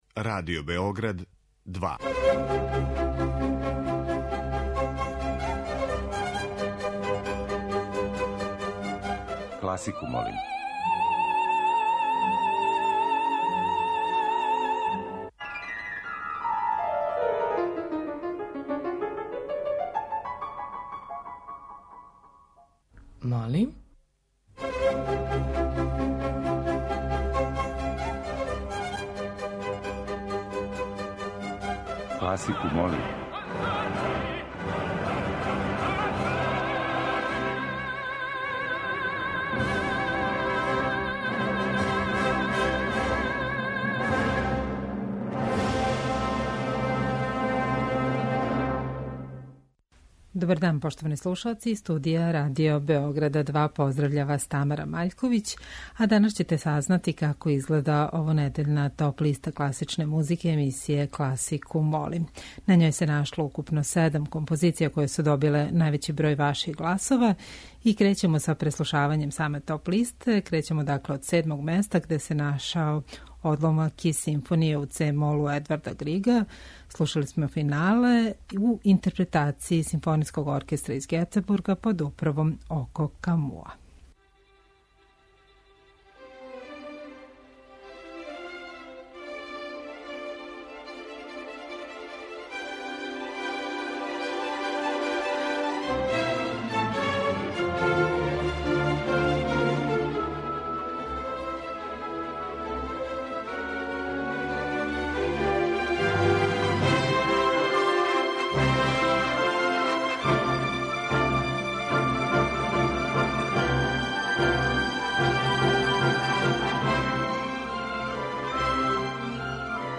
Тема циклуса су популарна Ноктурна различитих аутора.